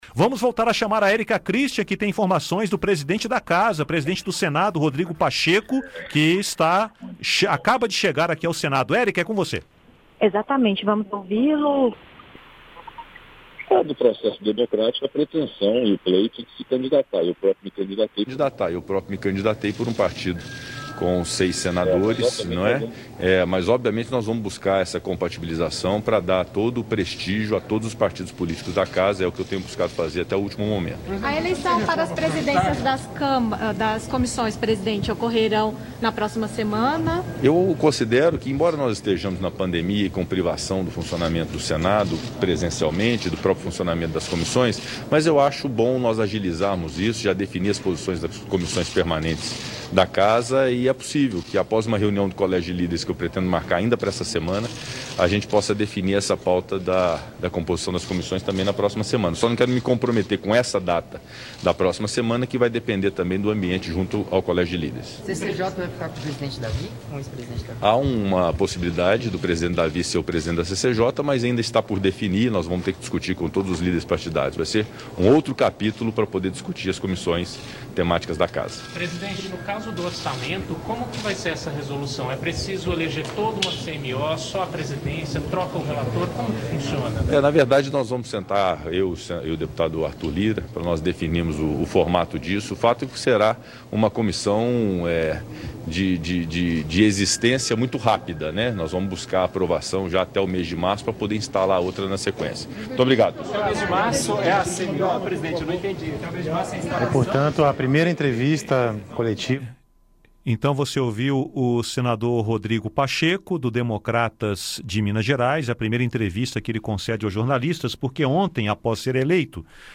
O presidente do Senado, Rodrigo Pacheco, concedeu uma entrevista aos jornalistas ao chegar ao Senado nesta terça-feira (2). Ele afirmou que defende diálogo para definir o 1º vice-presidente do Senado, mas que, se não houver acordo, os senadores vão definir no voto quem vai ocupar a vaga. Rodrigo Pacheco disse que pretende definir os presidentes das comissões do Senado na próxima semana.